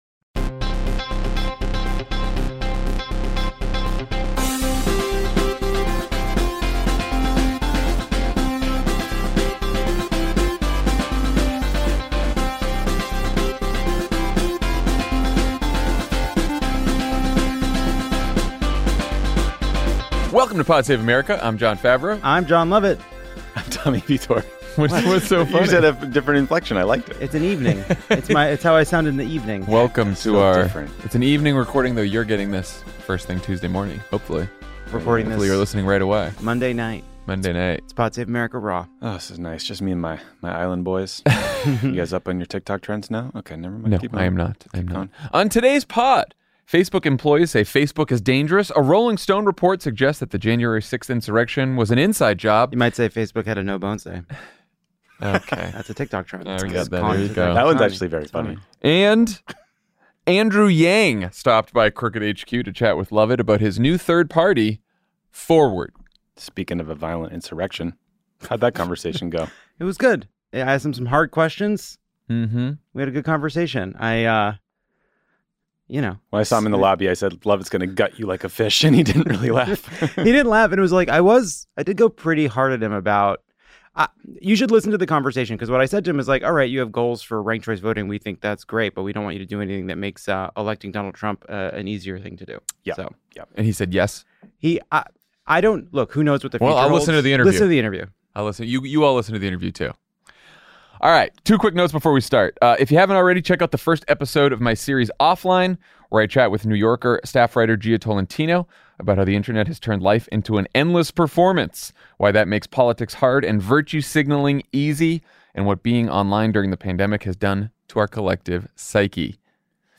Facebook employees say Facebook is dangerous, a Rolling Stone report suggests that the January 6th insurrection was an inside job, and Andrew Yang stopped by Crooked HQ to chat with Jon Lovett about his new third party, Forward.